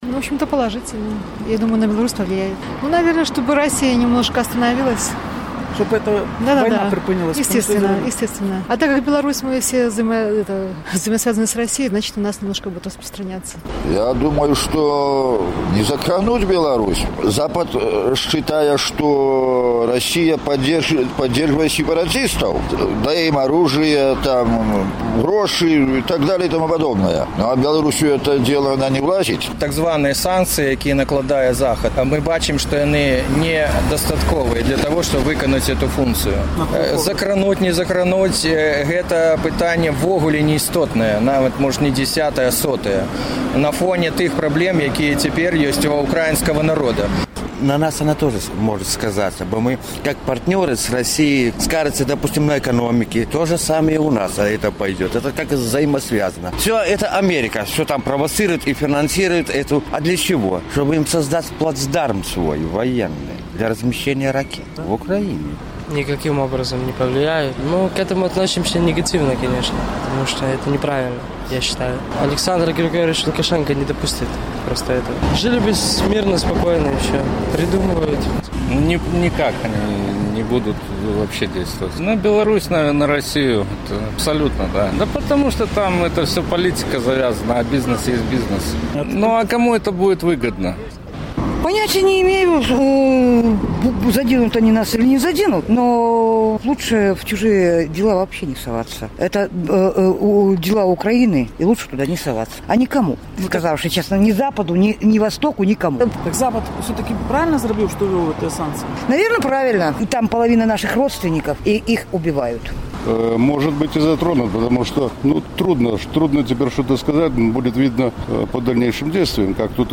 З такімі пытаньнямі наш карэспандэнт зьвяртаўся да гарадзенцаў.